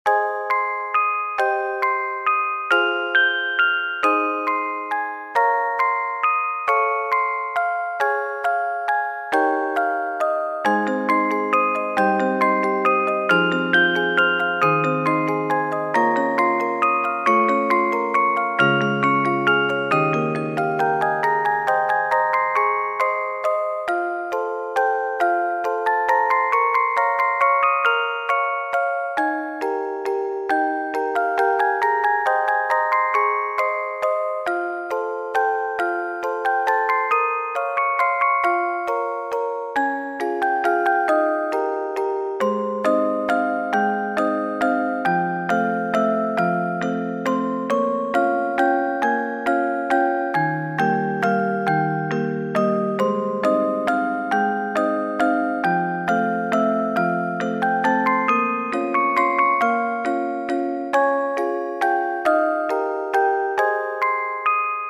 ogg(L) しっとり 癒し オルゴール
あたたかいオルゴール。